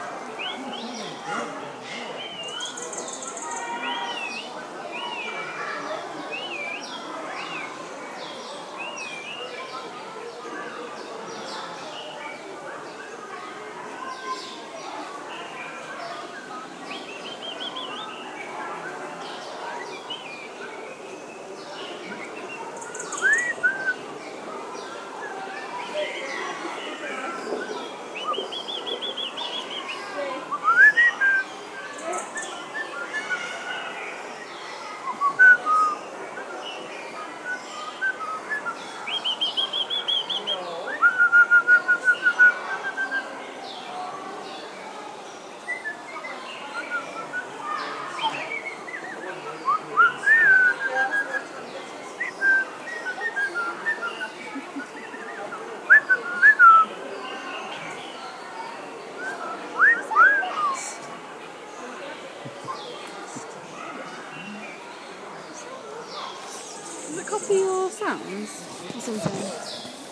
Snowy Crowned Robin Chat